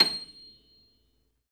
53d-pno26-C6.aif